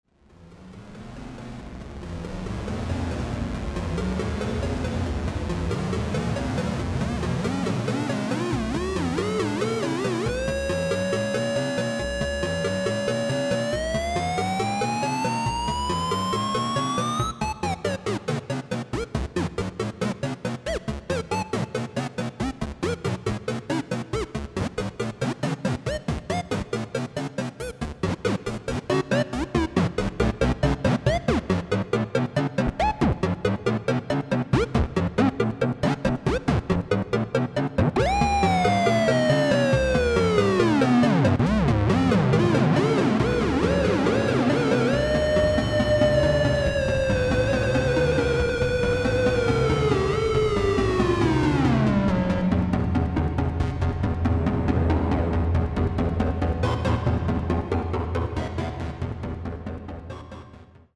prototype electronic musical instruments
Unlike the self destructing vacuum tube circuits the Barrons used, the Martian Music Machines are solid state integrated analog synthesizers similar to the instruments made during the mid 1960's by Bob Moog and Don Buchla. Many of the complex tonal modulation sounds seem to take on lives of their own as they sing or sometimes scream in and out of existence.